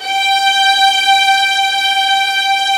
ZG3 STRS G4.wav